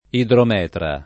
vai all'elenco alfabetico delle voci ingrandisci il carattere 100% rimpicciolisci il carattere stampa invia tramite posta elettronica codividi su Facebook idrometra [ idrom $ tra ] s. f. (med. «idropisia dell’utero»)